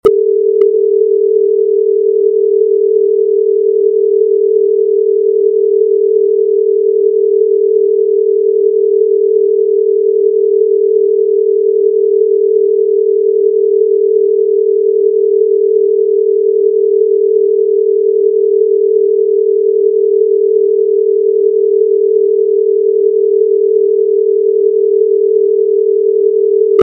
40Hz Gamma: Your Brain's 'Performance sound effects free download